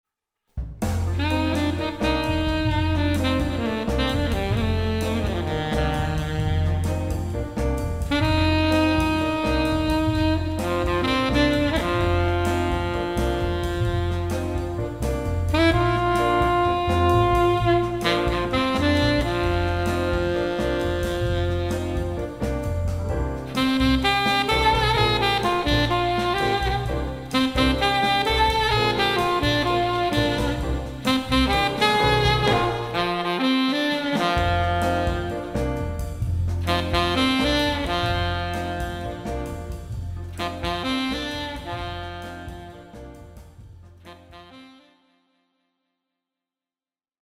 SAX & VOICE
Bluesig-Groovig: